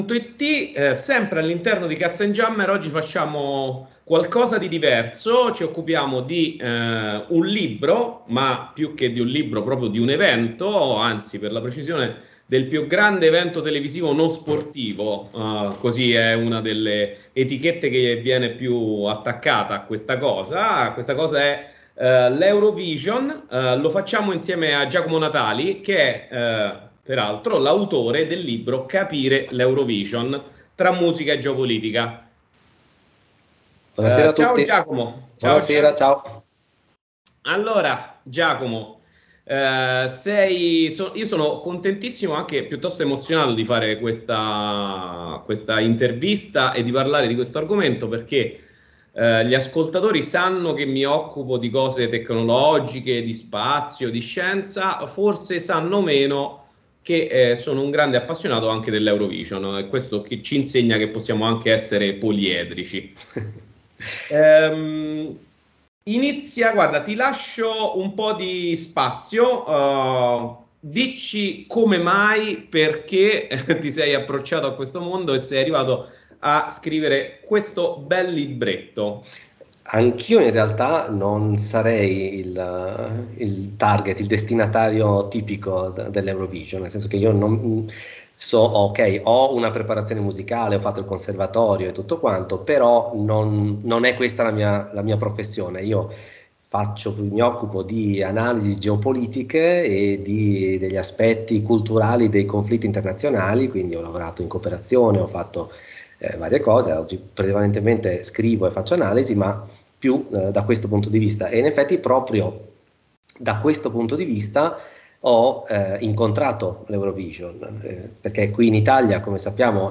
Una chiacchierata sulla musica, sulla geopolitica, sullo spettacolo e sull’Europa. Farcita da qualche grande successo proprio delle edizioni passate dell’Eurovision.